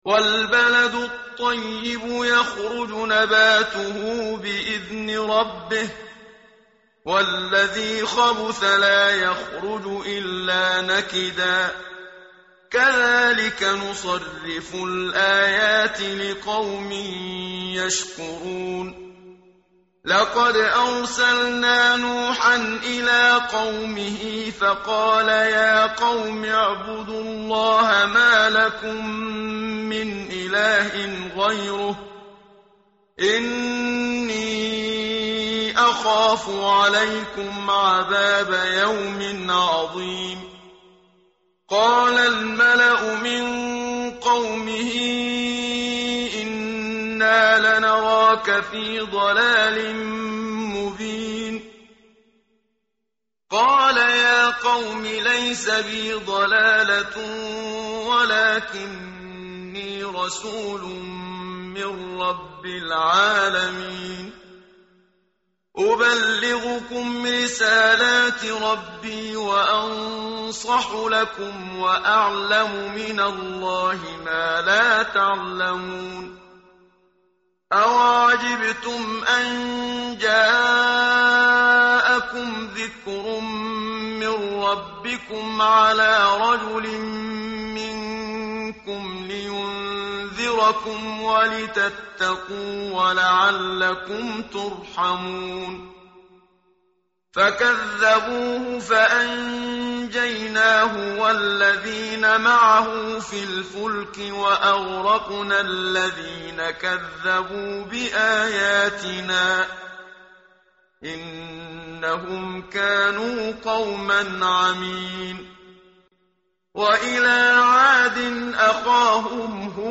tartil_menshavi_page_158.mp3